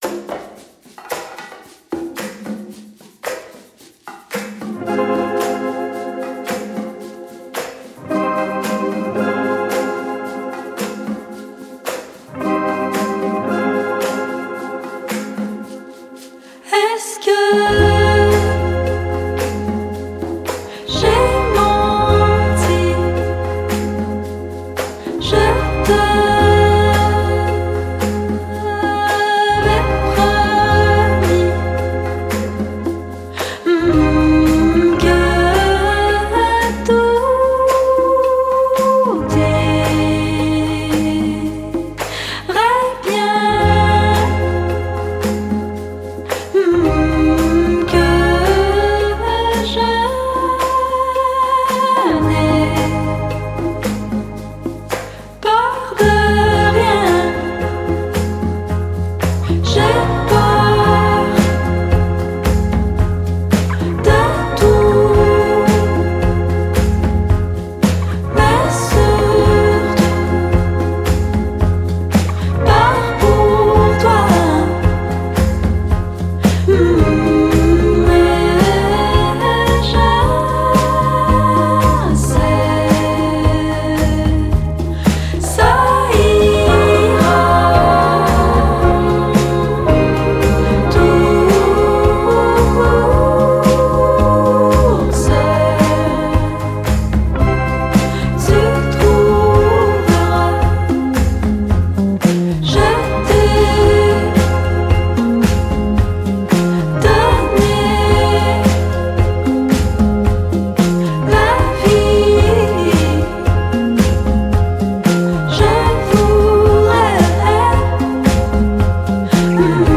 à la basse
à la guitare
à la batterie
aux claviers